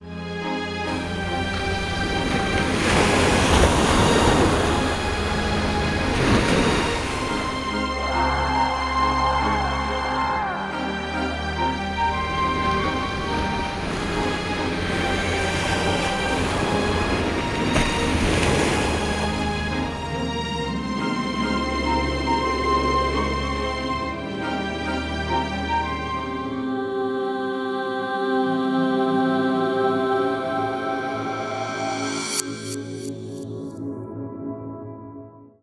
Cole’s paintings are biblical and operatic, so this soundscape contains a symphonic tune throughout the track. There are waves crashing to indicate the turbulence of the scene, and also vocal snarling, a reference to the demonic presence of “despairing thoughts” in this piece. The track ends with angelic, gentle ambience, to note the calm beauty in the far horizon of the painting.